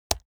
Audio Requirement: To enable the typing sound effects, you should download the required audio file and place it in your project's public/audio directory.
key-press.wav